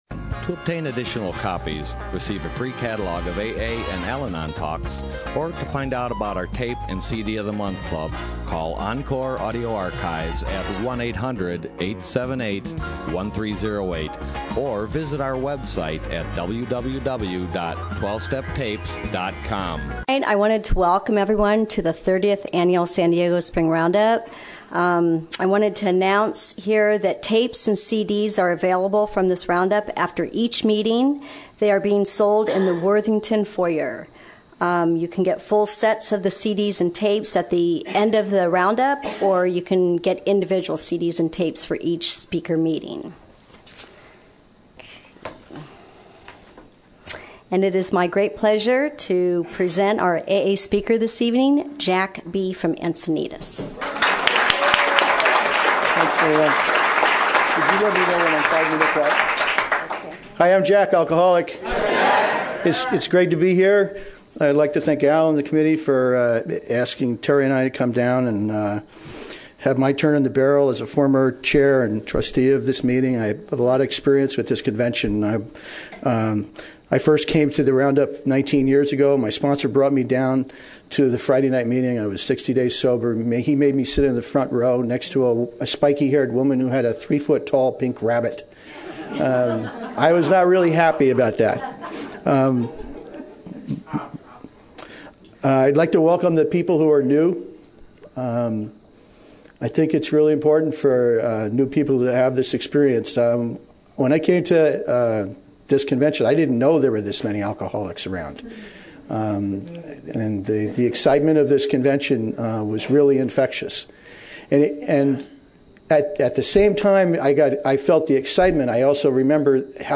San Diego Spring Roundup 2007